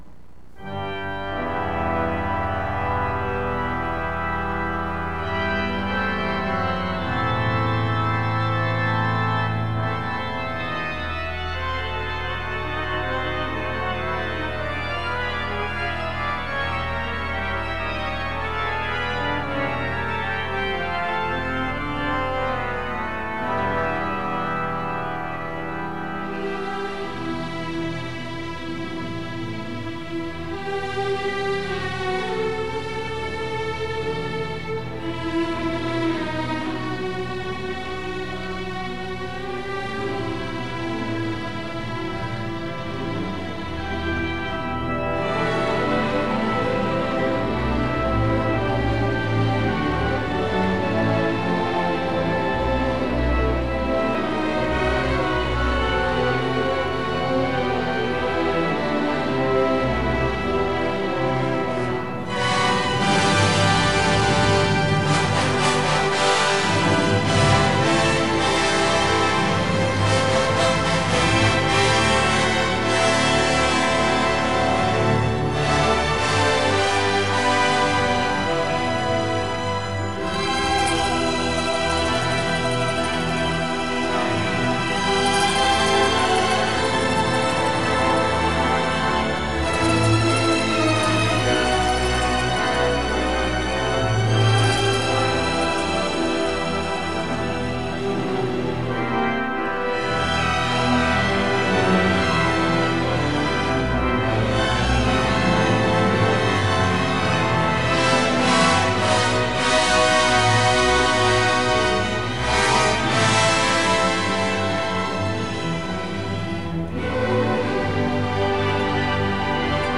Original track music: